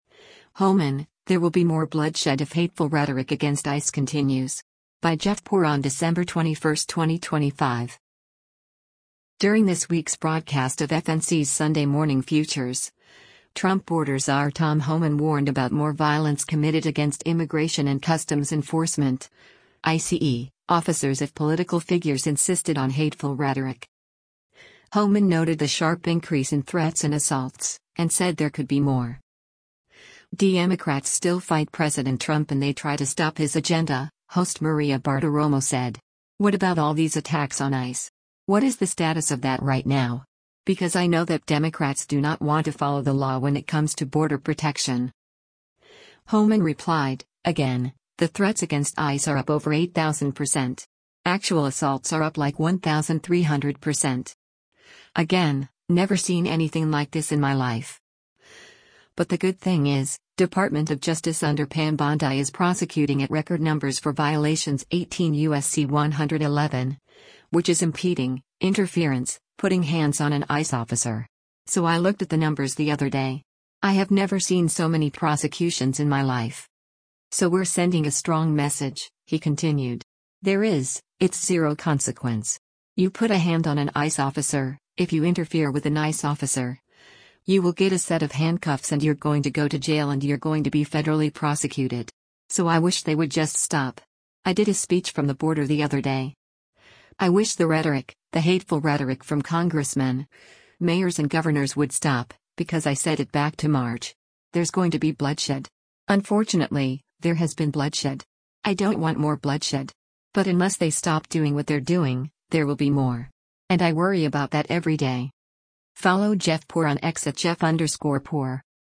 During this week’s broadcast of FNC’s “Sunday Morning Futures,” Trump border czar Tom Homan warned about more violence committed against Immigration and Customs Enforcement (ICE) officers if political figures insisted on “hateful rhetoric.”